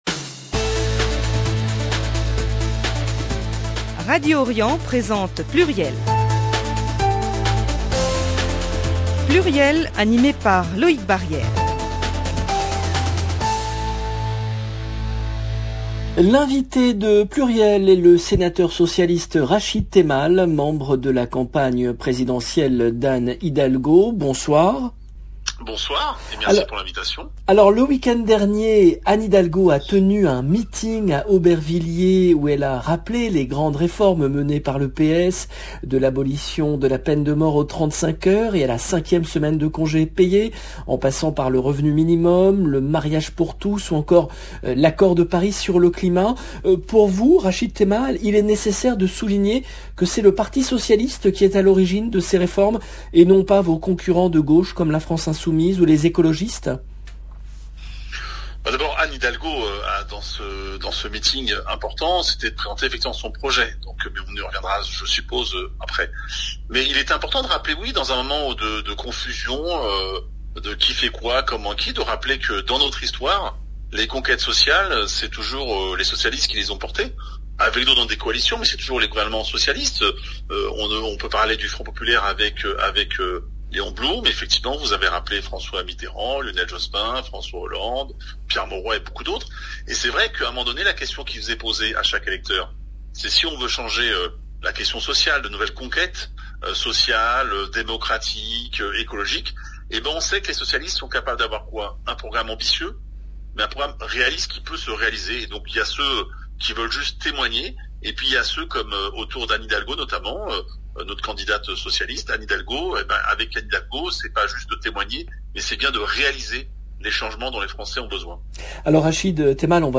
L’invité de PLURIEL est le sénateur socialiste Rachid Temal , membre de la campagne présidentielle d’Anne Hidalgo